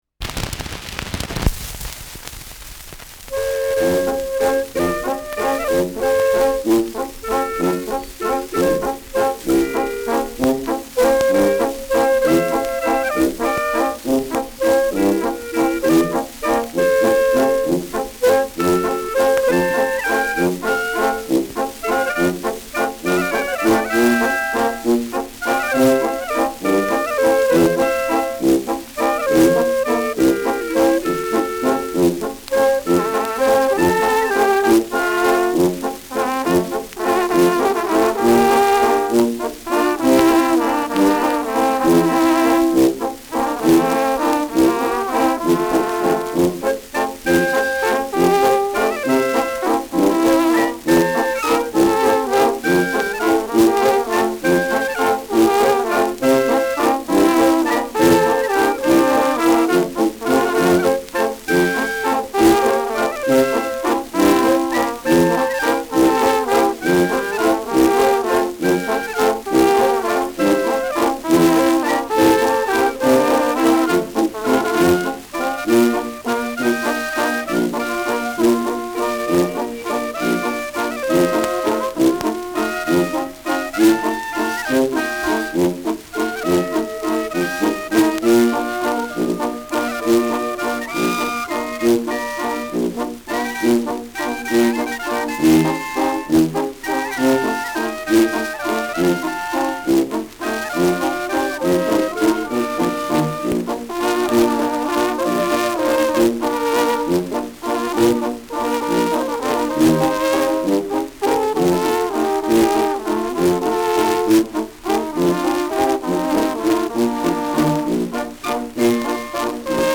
Schellackplatte
Bass verzerrt
[Nürnberg] (Aufnahmeort)